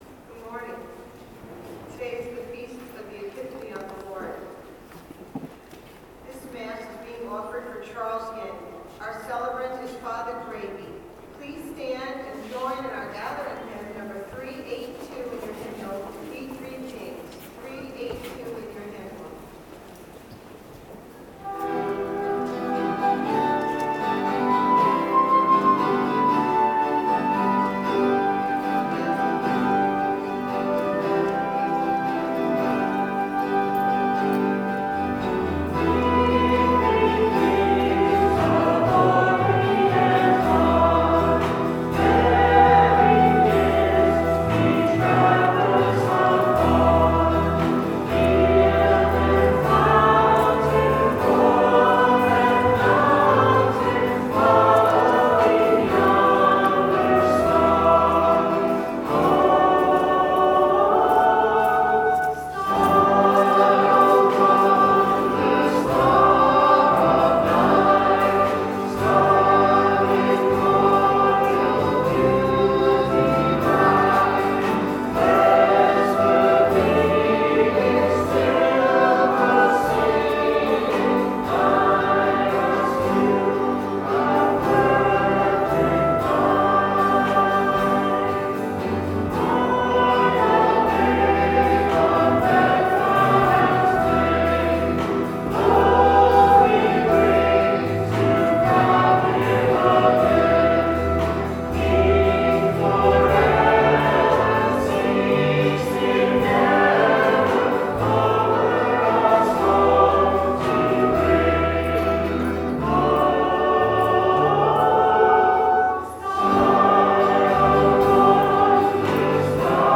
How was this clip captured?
01/05/14 Mass Recording of Music